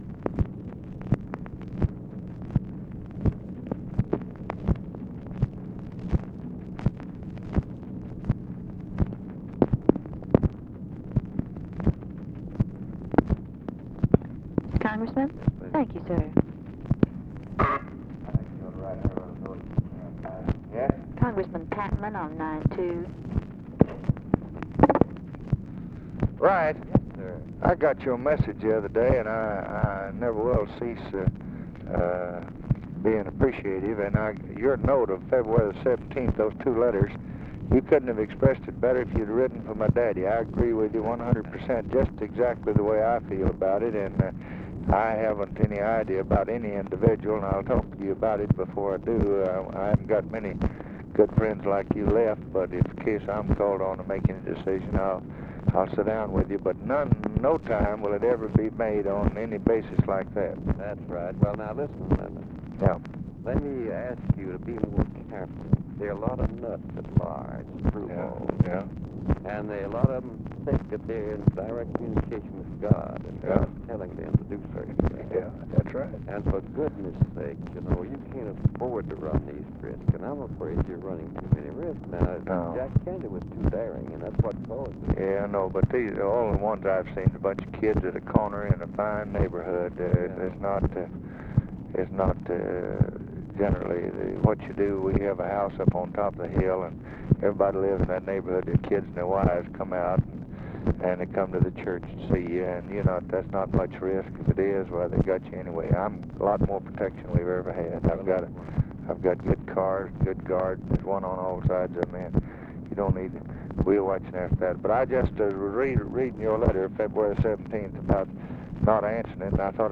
Conversation with WRIGHT PATMAN, February 24, 1964 | Miller Center
Secret White House Tapes